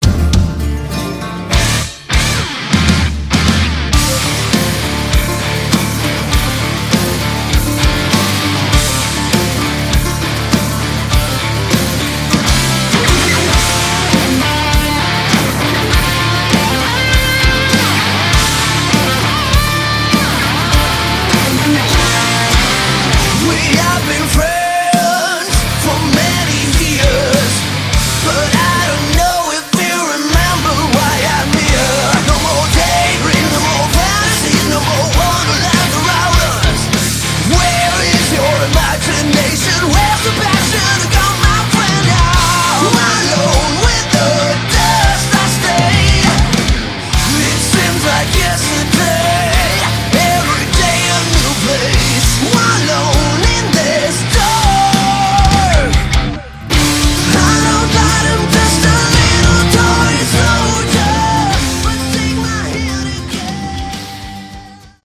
Category: Hard Rock
vocals
bass
guitars
drums